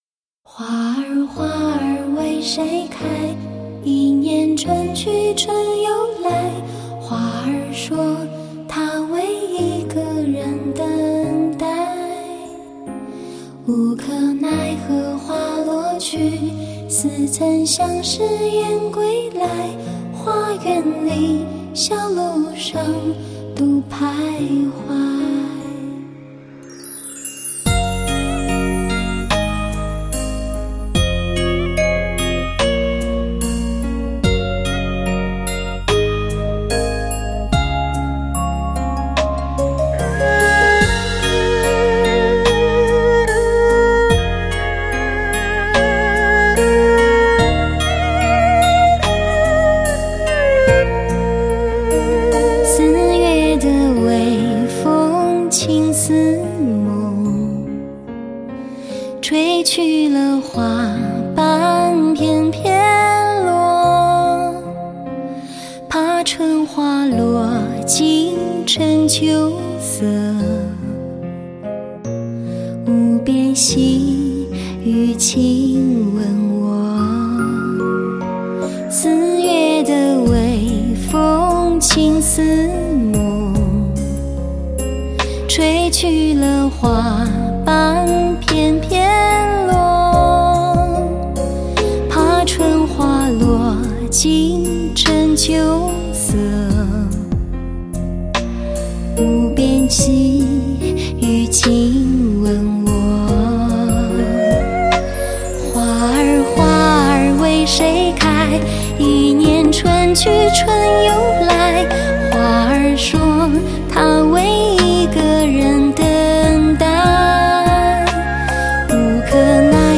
歌手有着独特的音色，仿佛可以站在你的角度唱出你想听的歌。